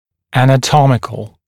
[ˌænə’tɔmɪkl][ˌэнэ’томикл]анатомический, морфологический (также anatomic)